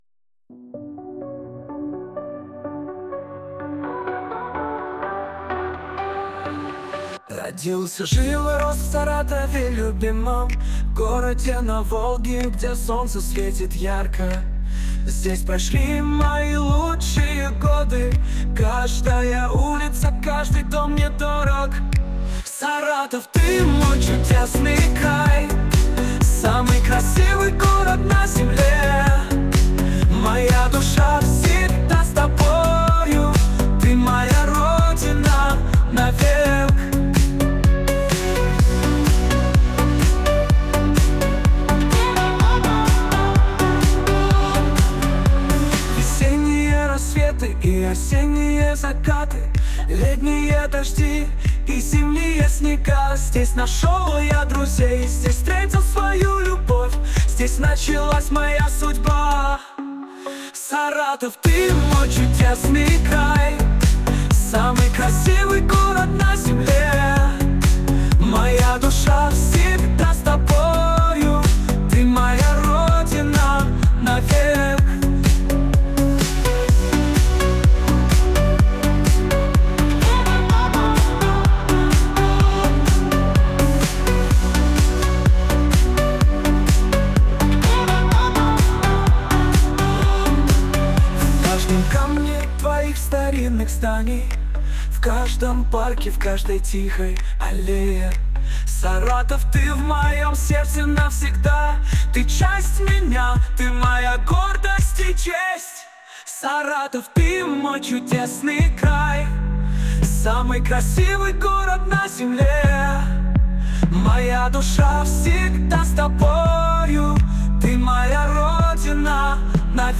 Вот пример песни созданной искусственным интеллектом: